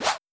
Slash.wav